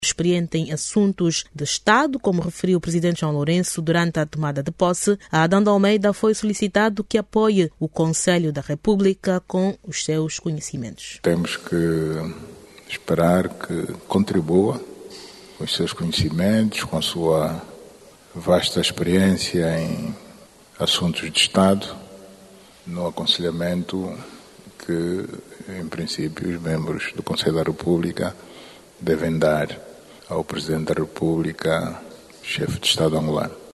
O novo presidente da Assembleia Nacional, Adão de Almeida, tomou posse, nesta quarta-feira, como membro do Conselho da Republica. Adão de Almeida foi empossado por inerência de funções, pelo Presidente da Republica, João Lourenço, dois dias depois de assumir a liderança do Parlamento Angolano. Saiba mais dados no áudio abaixo com a repórter